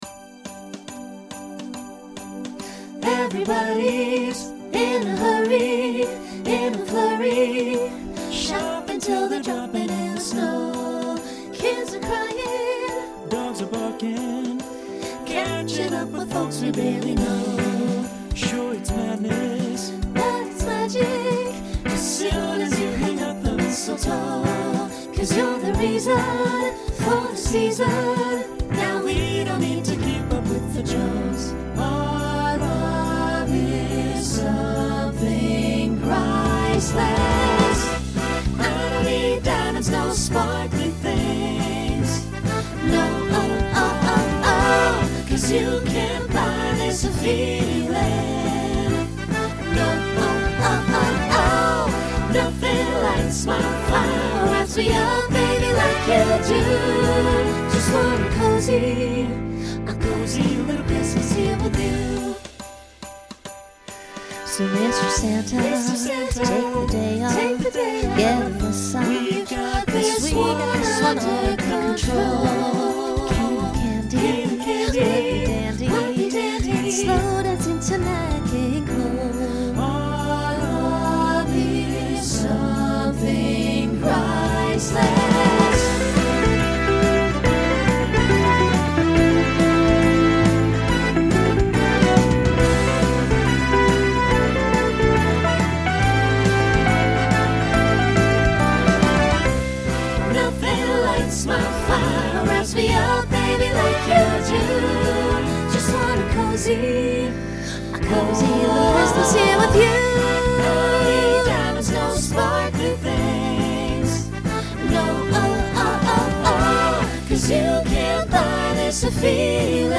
Genre Holiday , Pop/Dance , Swing/Jazz
Voicing SATB